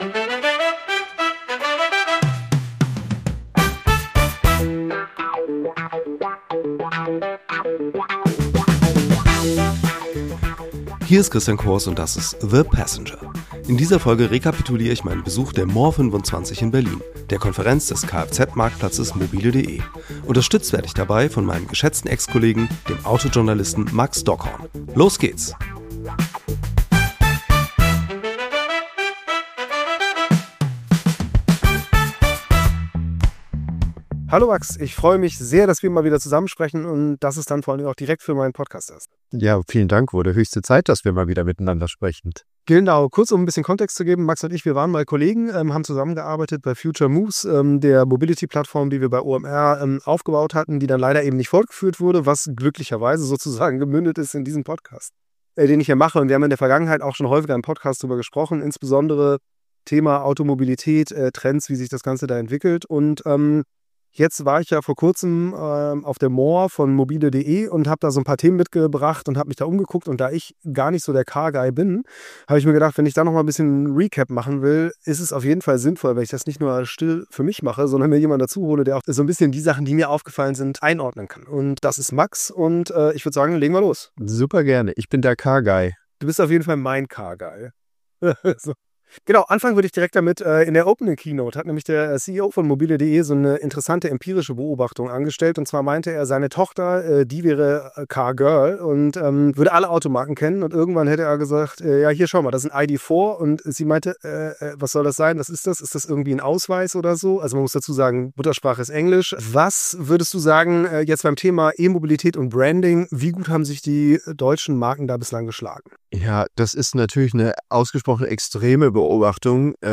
Hier reden sie über Trends, die das Auto uns den Handel damit verändern werden.